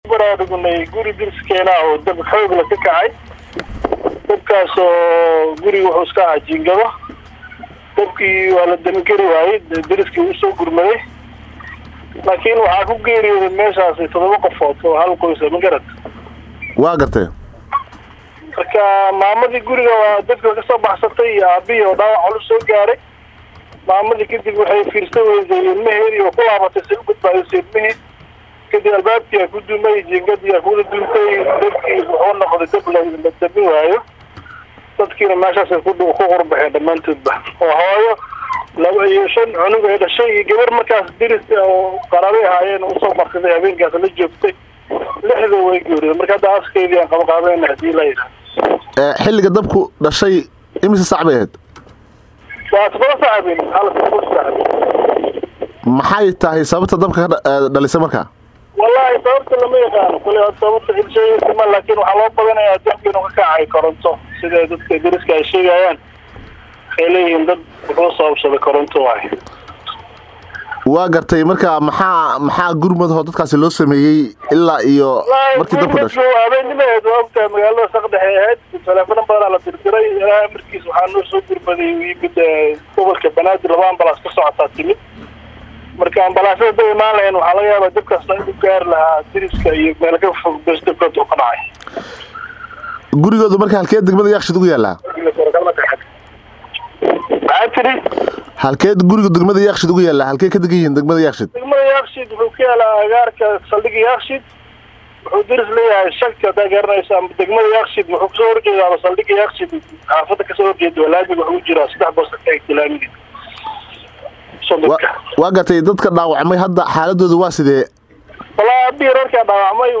April 24, 2026 Mid ka mid ah dadka degan xaafadadda xalay dadku uu ka kacay ayaa Radio Muqdisho faah faahin ka siiyay sida dadku ku dhashay isagoona tilmaamay in Saaka aas u samaynayaan qoyskii xalay masiibadu kasoo gaartay Dabkii. Halkaan hoose ka dhageyso Codka Goobjoogaha: